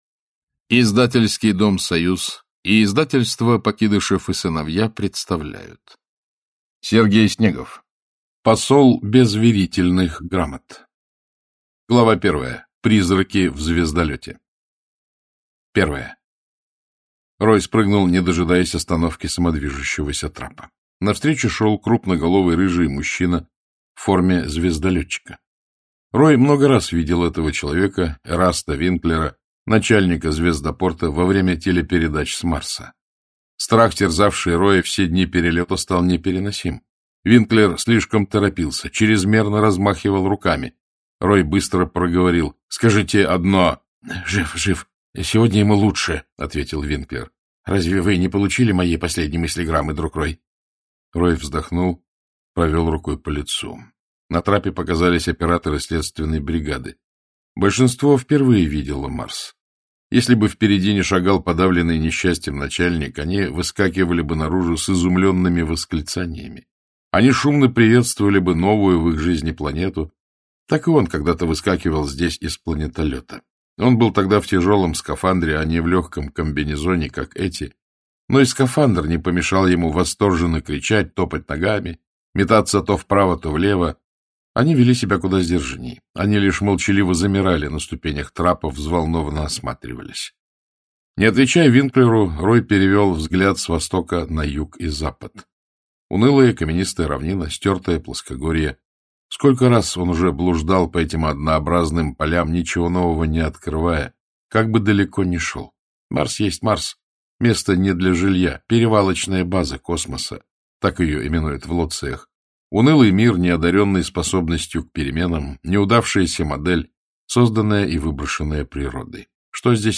Aудиокнига Посол без верительных грамот Автор Сергей Снегов Читает аудиокнигу Александр Клюквин.